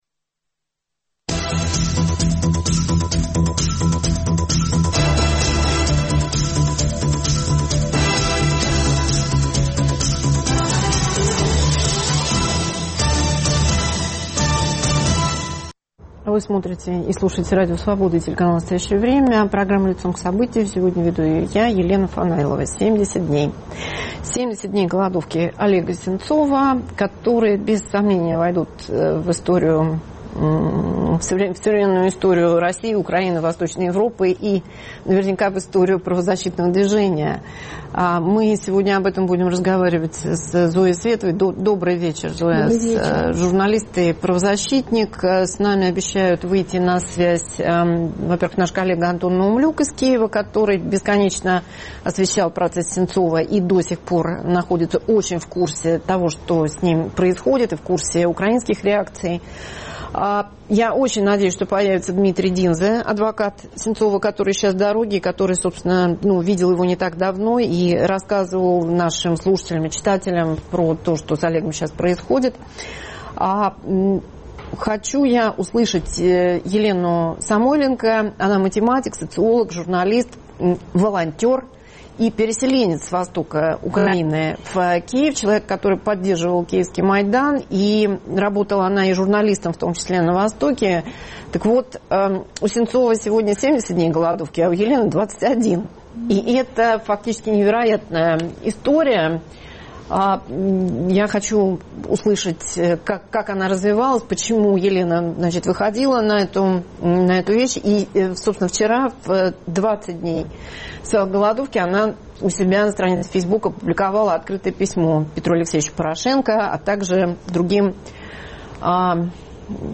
Обсуждают правозащитники и общественные деятели